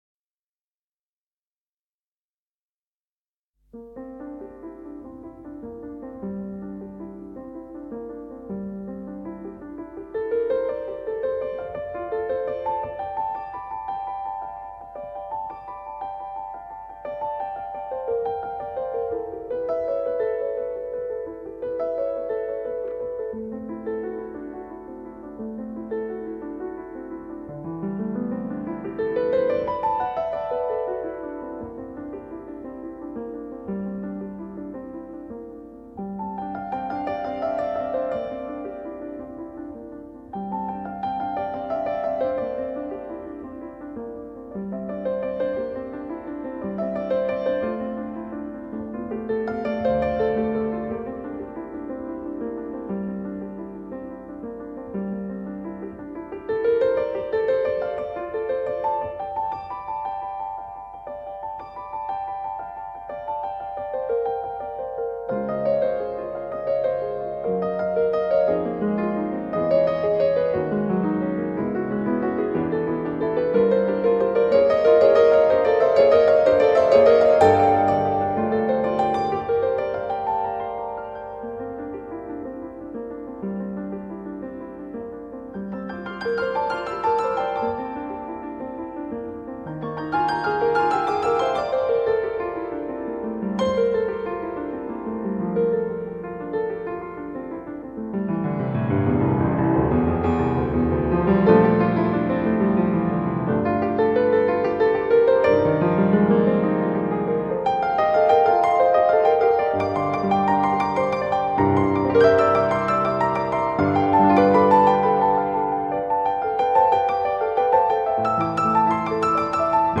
全新数码音频处理